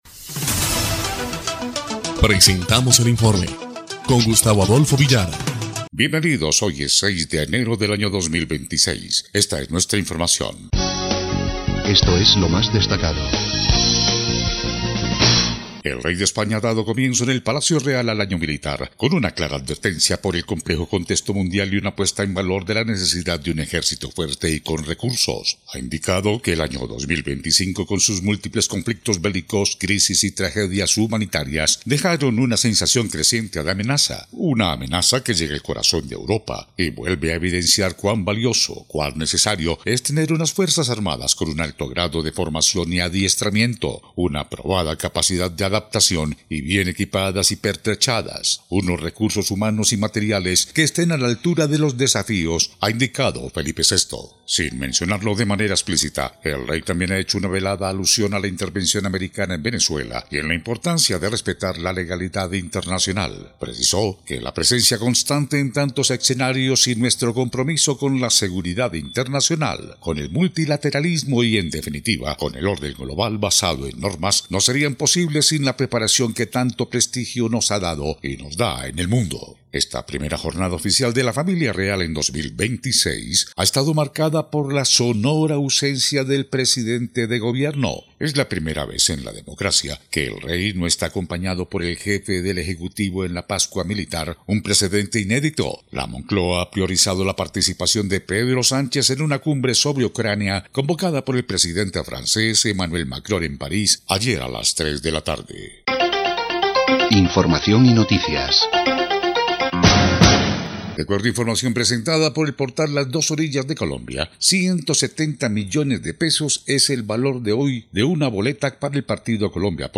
EL INFORME 1° Clip de Noticias del 7 de enero de 2026